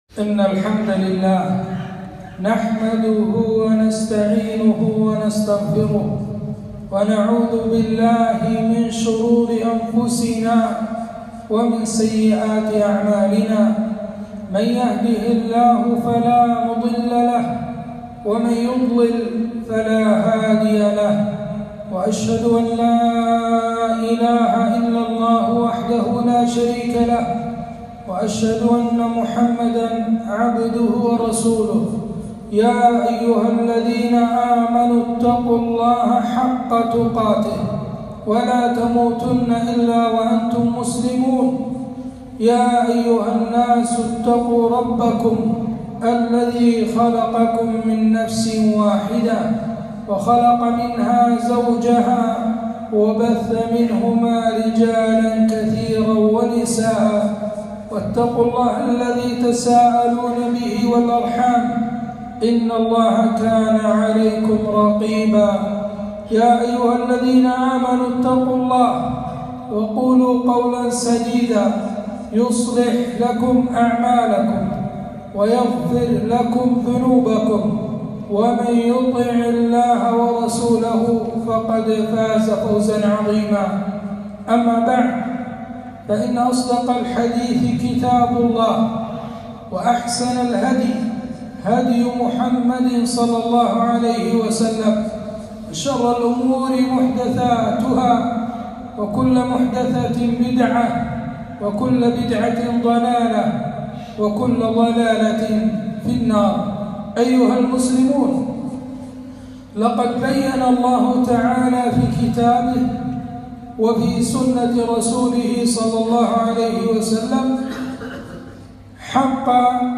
خطبة - الحقوق الزوجية في الإسلام